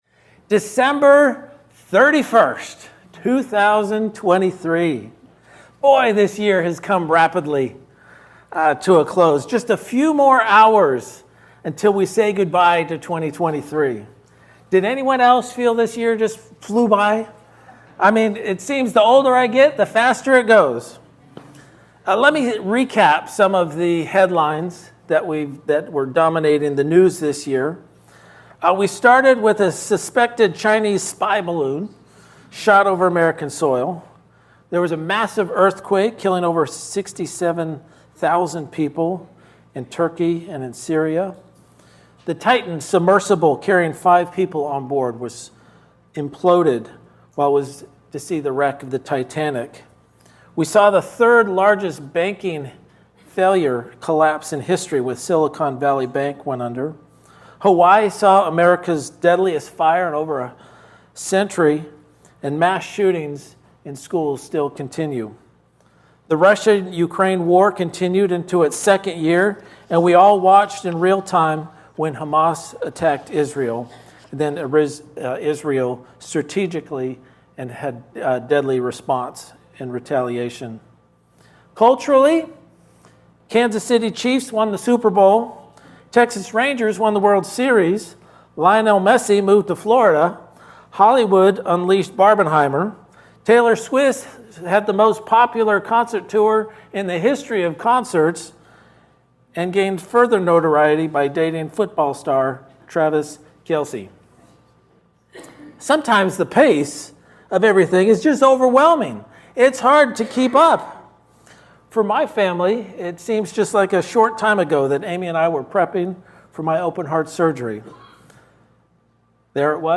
Guest Speaker, Standalone Sermon